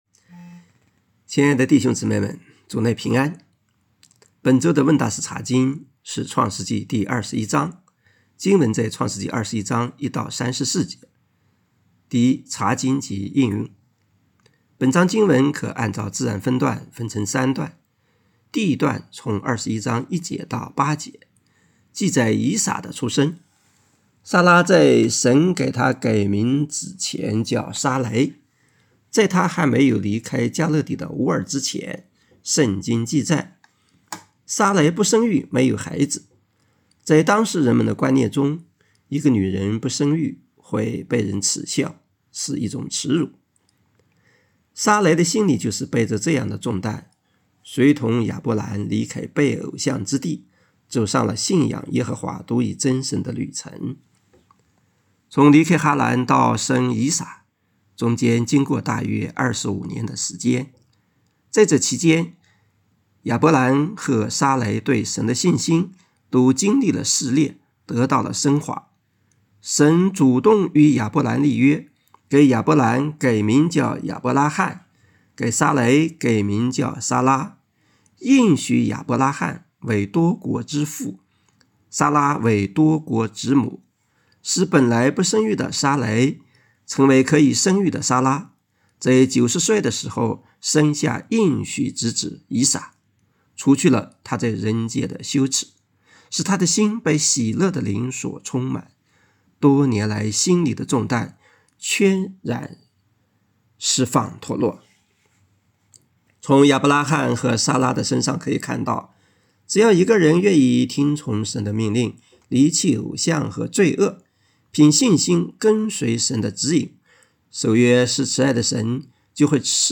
问答式查经——《创世记》（21）